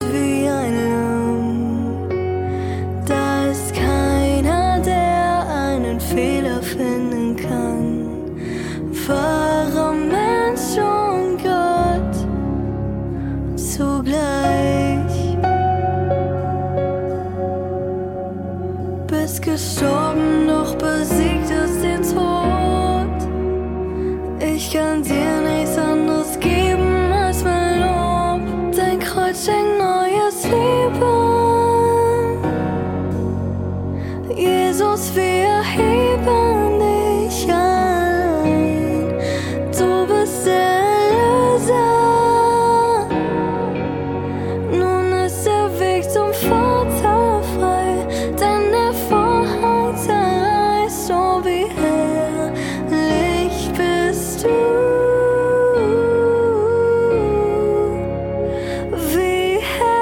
Lobpreis
Gesang